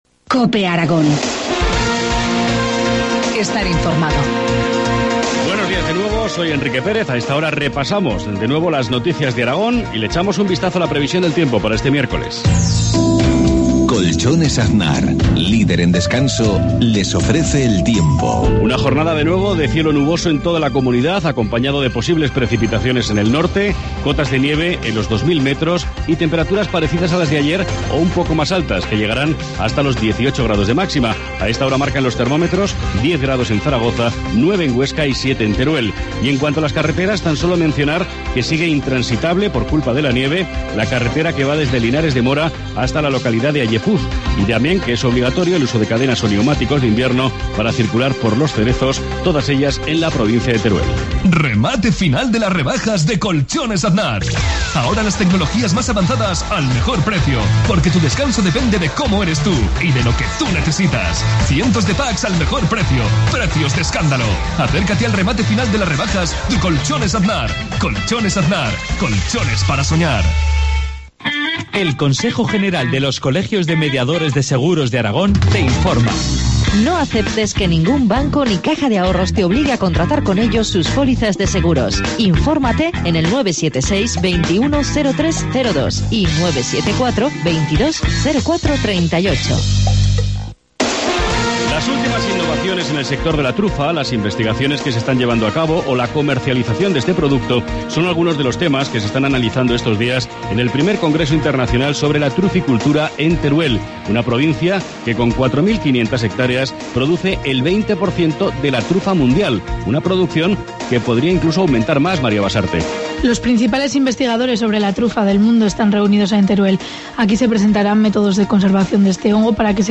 Informativo matinal, martes 6 de marzo, 7.53 horas